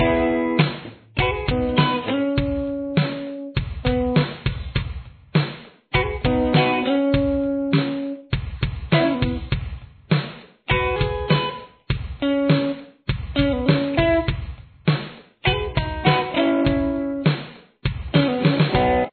Verse
Guitar 2